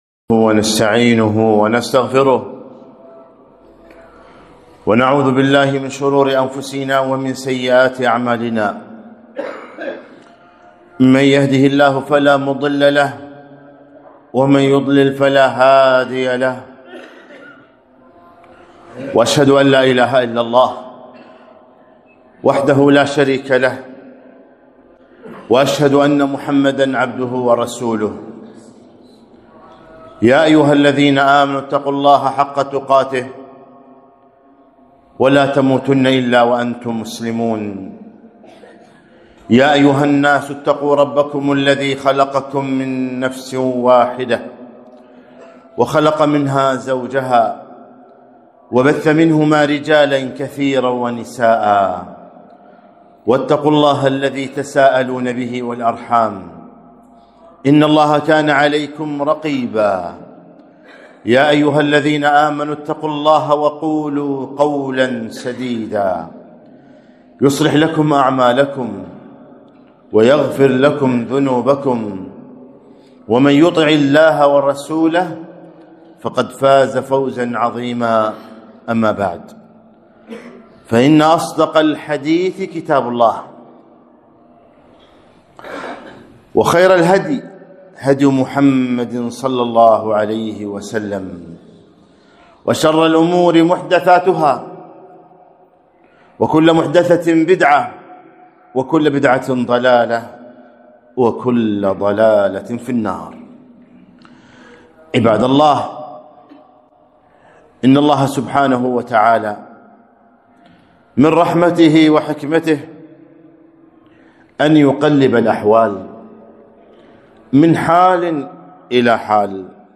خطبة - الشتاء عبر وأحكام